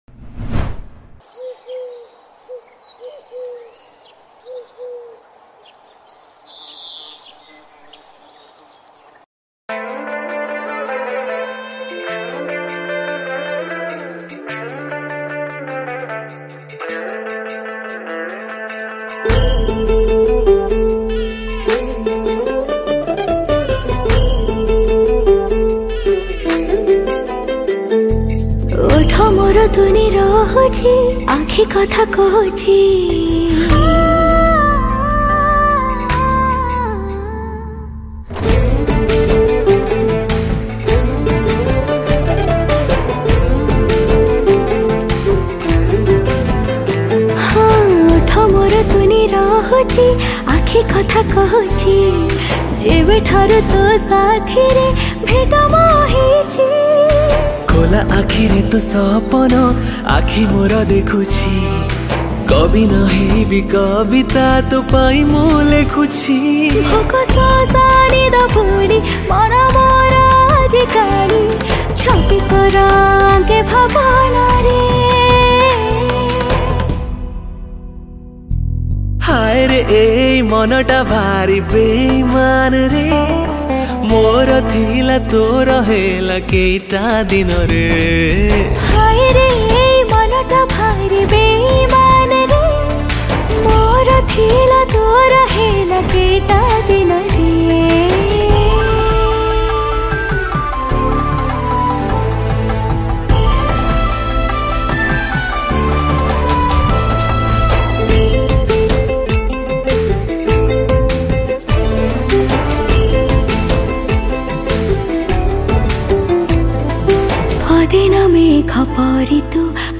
Guitar & ukulele